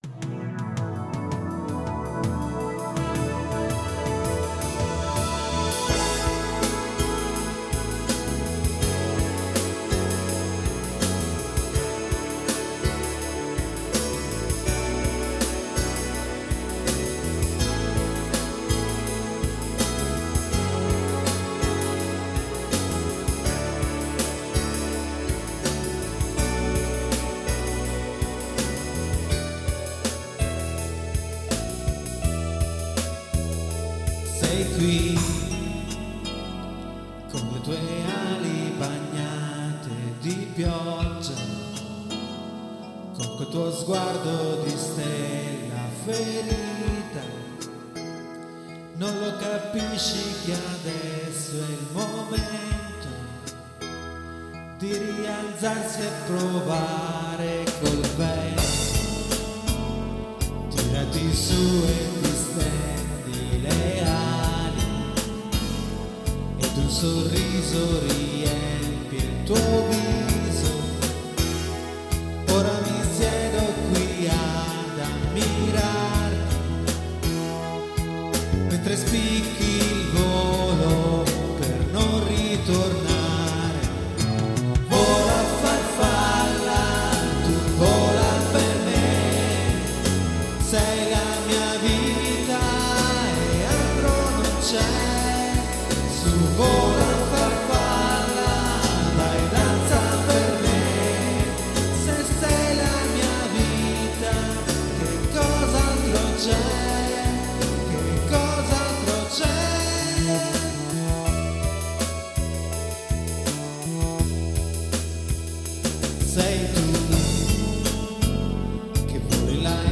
• Multitrack Recorder Zoom MRS-4
• Mic AKG D 40 S